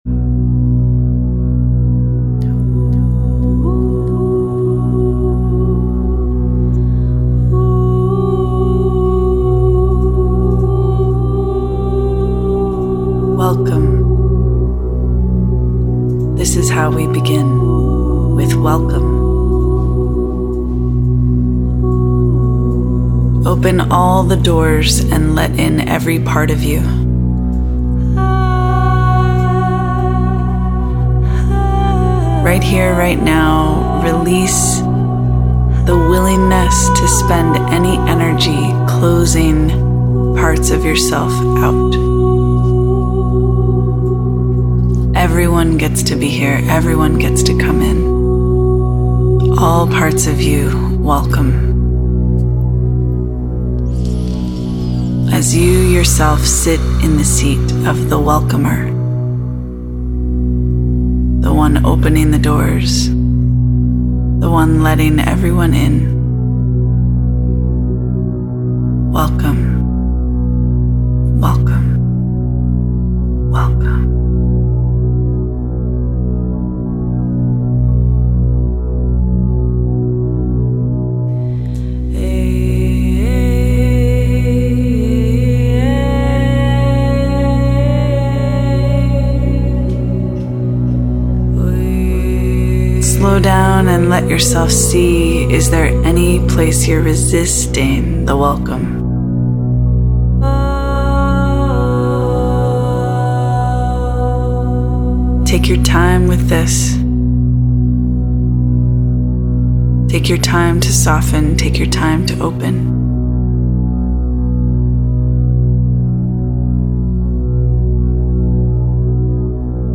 I recorded the Belief in Self meditation to work with these truths.